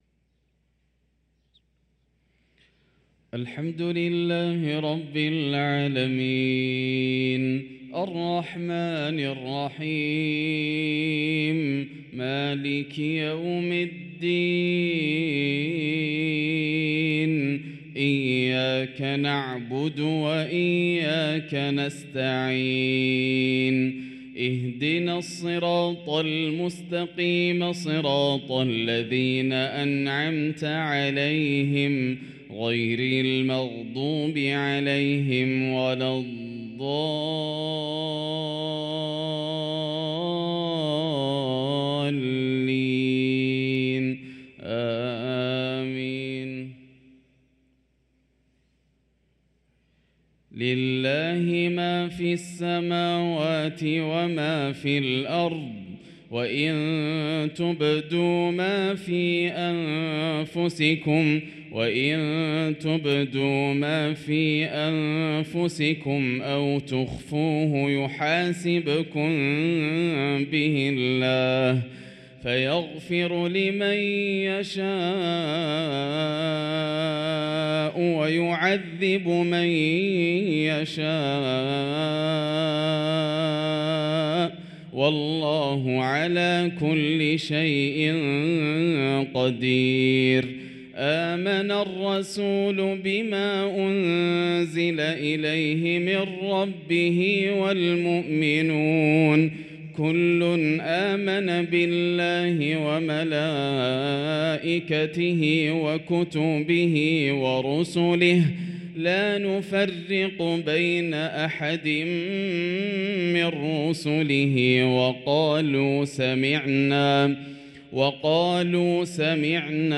صلاة المغرب للقارئ ياسر الدوسري 10 صفر 1445 هـ
تِلَاوَات الْحَرَمَيْن .